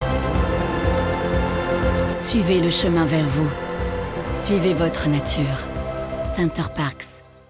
Voix off
Pub TV diffusée - Center Parcs Suivez votre nature (voix sincère, élégante)
25 - 35 ans - Mezzo-soprano